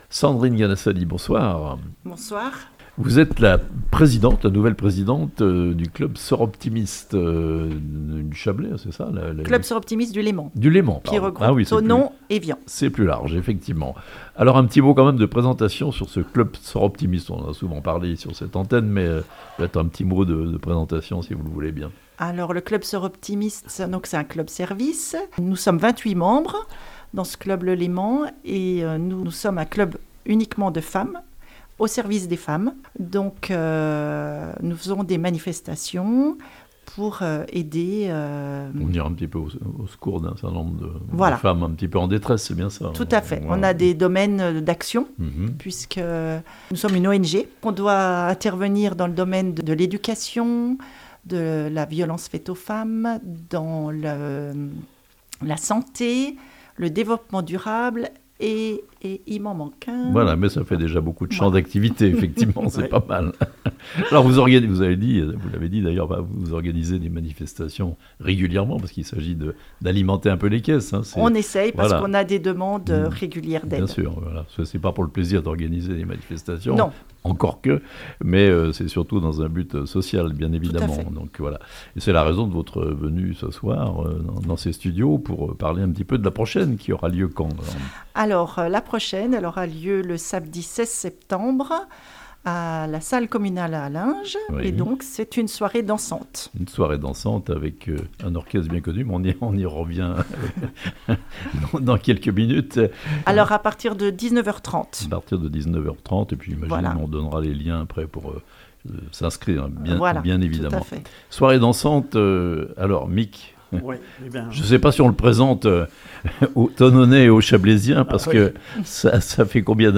(interviews)